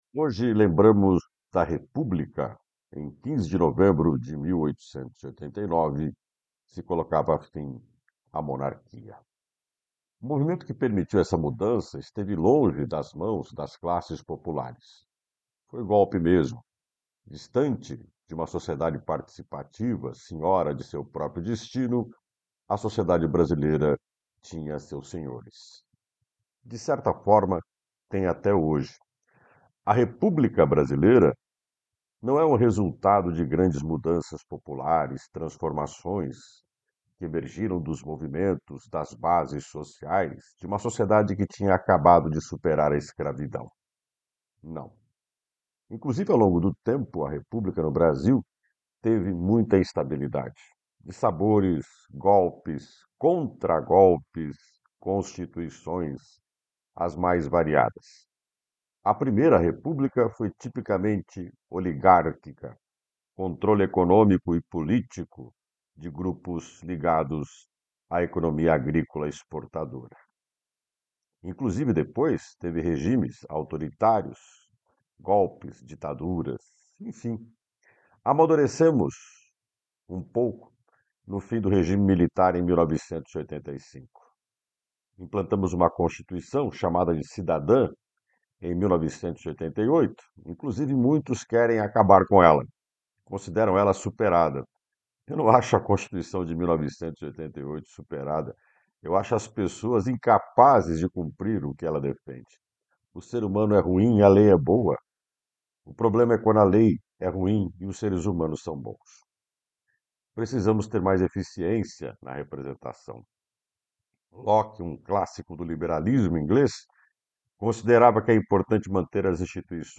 Opinião